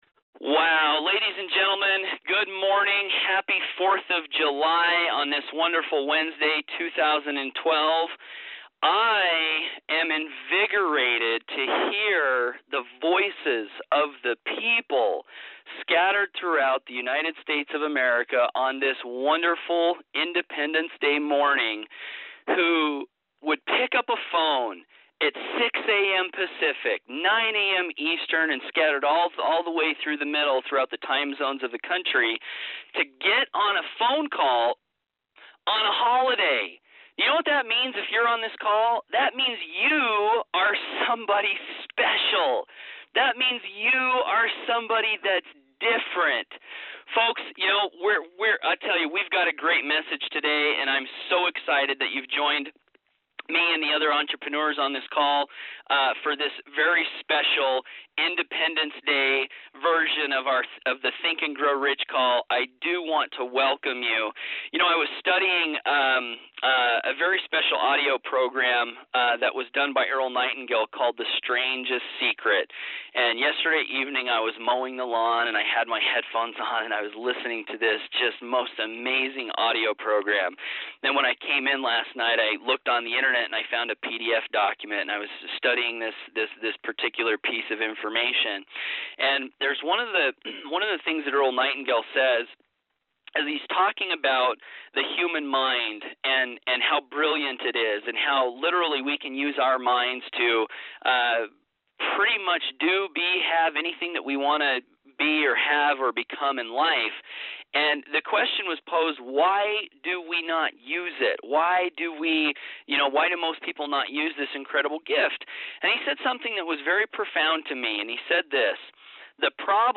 This is probably the most powerful call we’ve ever had.
Tears in eyes, passion, vision and gratitude.